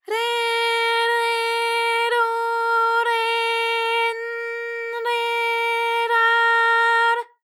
ALYS-DB-001-JPN - First Japanese UTAU vocal library of ALYS.
re_re_ro_re_n_re_ra_r.wav